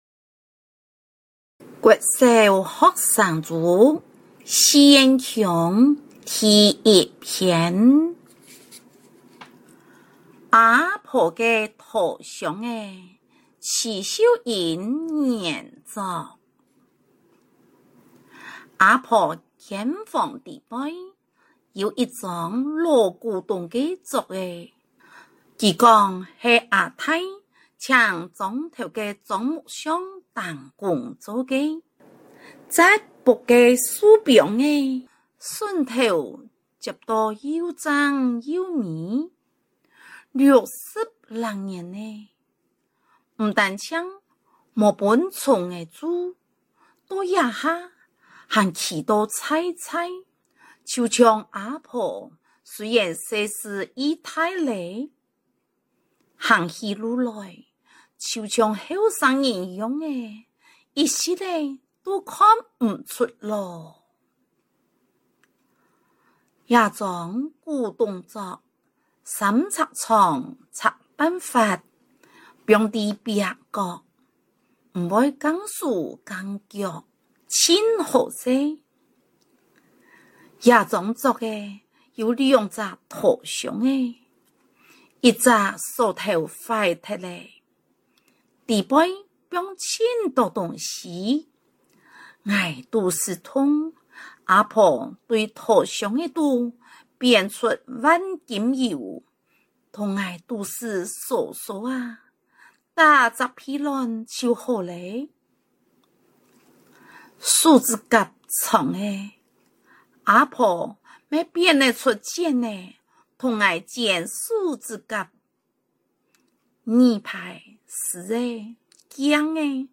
113全國語文競賽-朗讀授權文稿及授權錄音檔